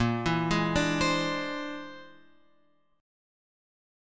A# 9th Flat 5th